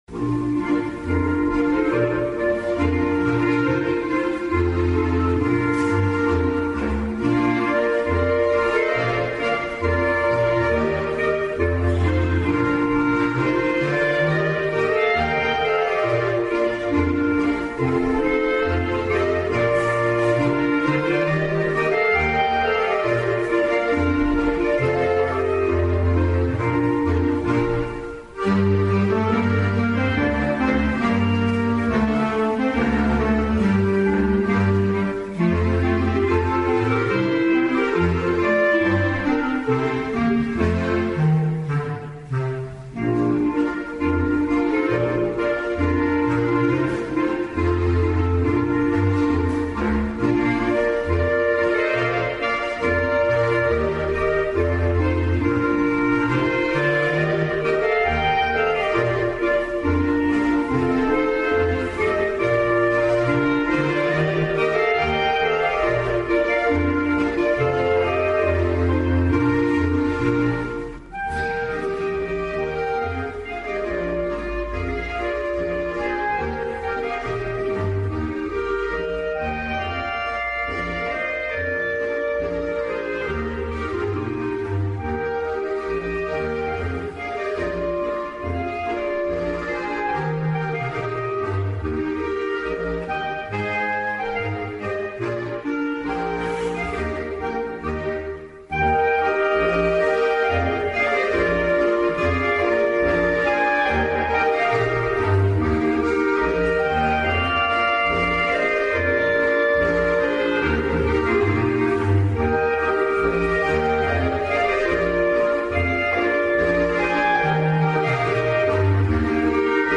Adventkonzert in Raaba/Graz
Unsere Adventmelodien waren eine schöne Ergänzung zu dem auf sehr hohem Niveau liegenden Chorkonzert.
Weihnachts-Boarischen" auch weitere Adventmelodien nicht fehlen.